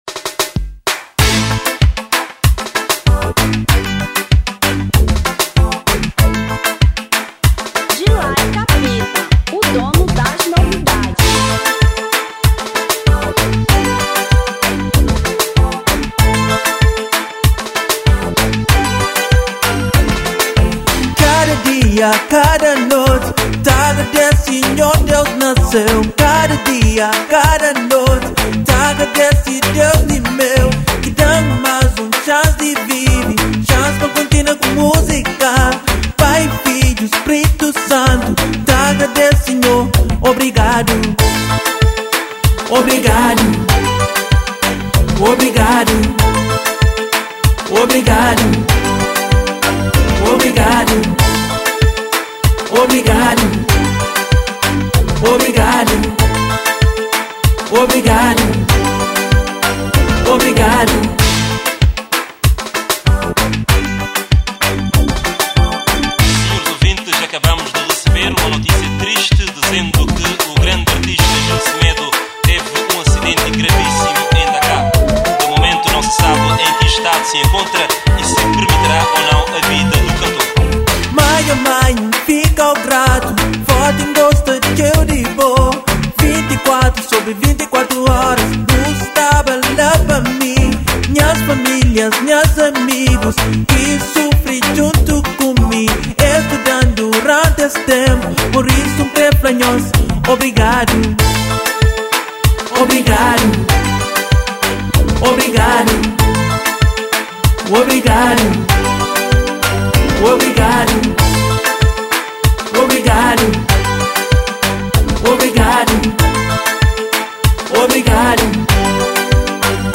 Kizomba 1995